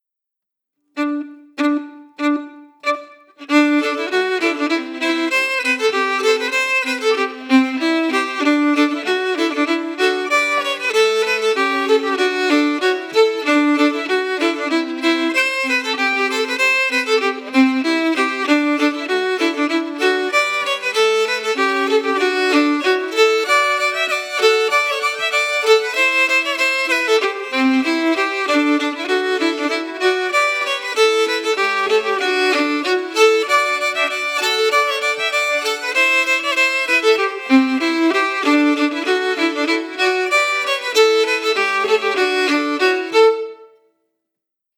Key: D-major
Form: Reel, Song
Melody Emphasis
Linkumdoddie-melody-emphasized.mp3